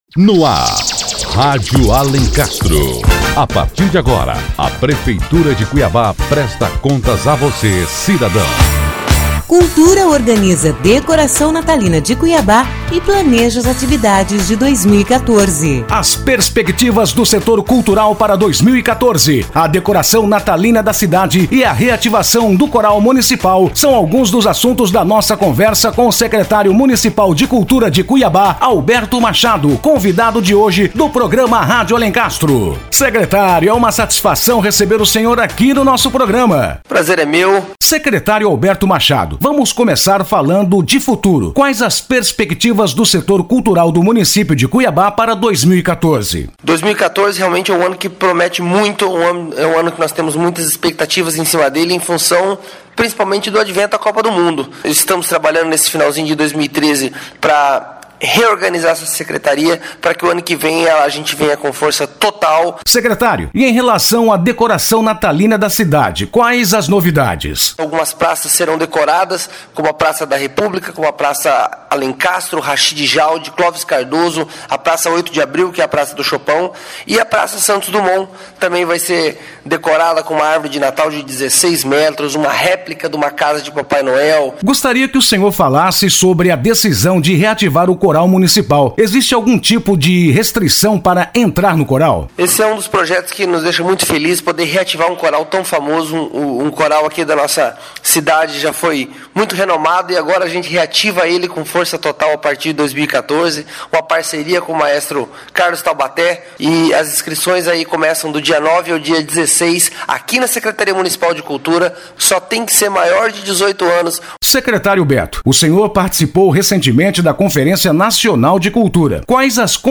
Conversa com o Secretário Municipal de Cultura | Notícias - Prefeitura de Cuiabá